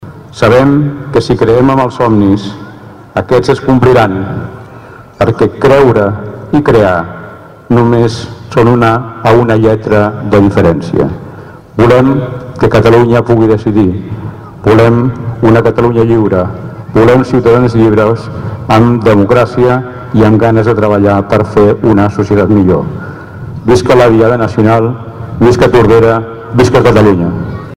L’Alcalde de Tordera va cloure el seu parlament recordant que els somnis es poden fer realitat i reivindicant el dret a decidir del poble català.
parlament-diada-2.mp3